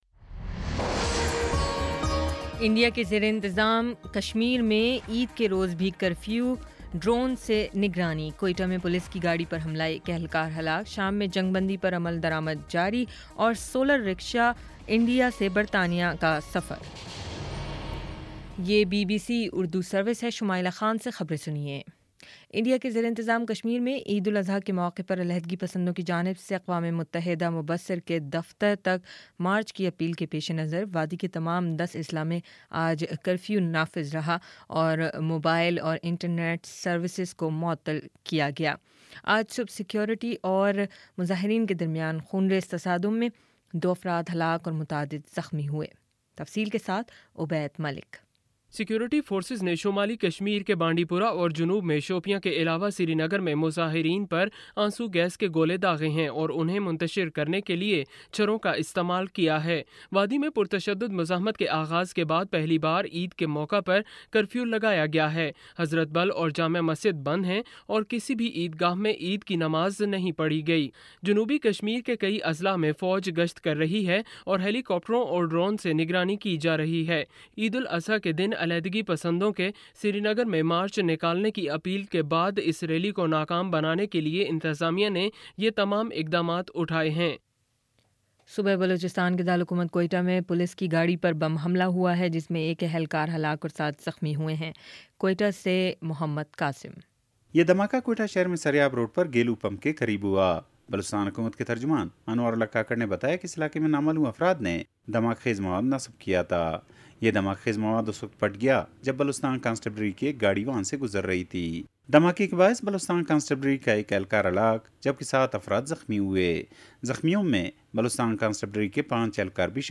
ستمبر 13 : شام سات بجے کا نیوز بُلیٹن